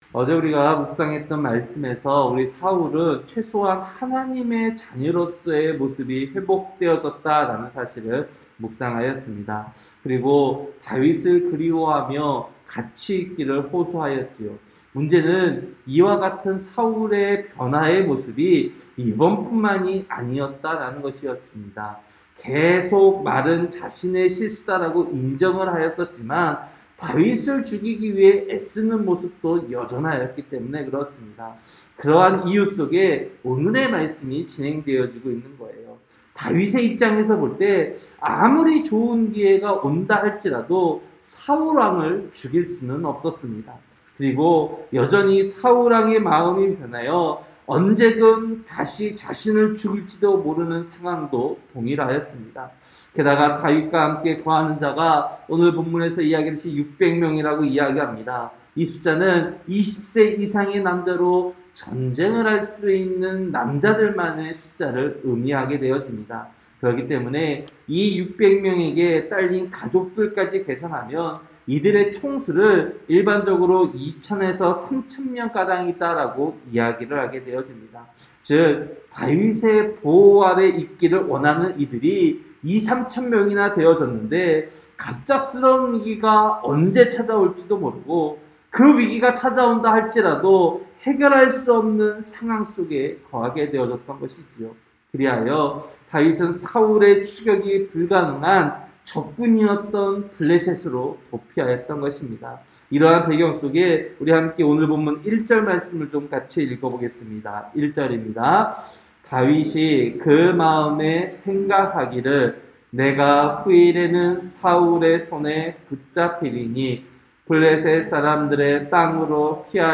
새벽설교-사무엘상 27장